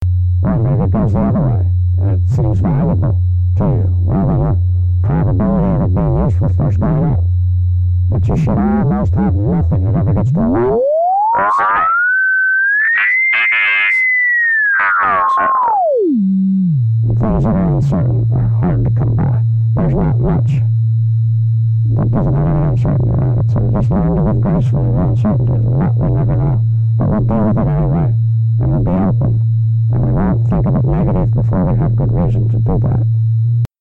描述：一个年轻的火腿无线电操作员通过短波进行通信。用假头麦克风录制。这是一个公共活动，向公众展示火腿无线电，因此气氛响亮。
标签： 火腿无线电 广播 观众 短波 传输 现场记录 业余无线电
声道立体声